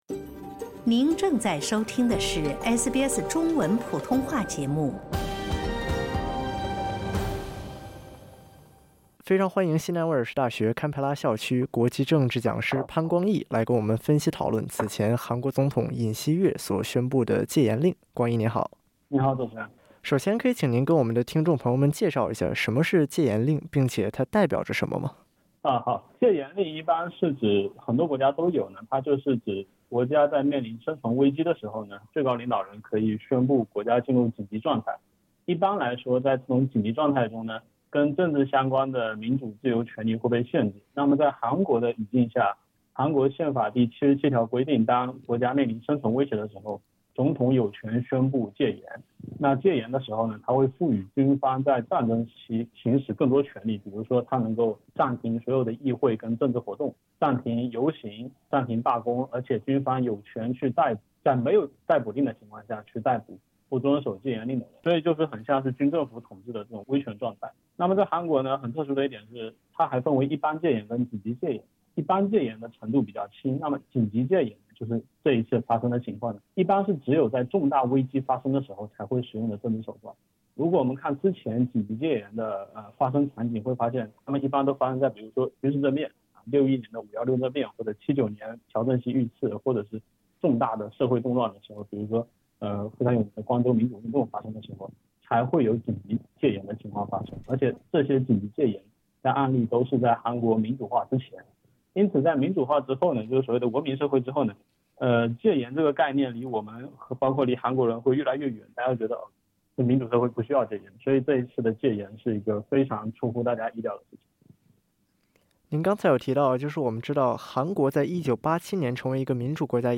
以上内容为嘉宾观点 不代表本台立场 欢迎下载应用程序SBS Audio，订阅Mandarin。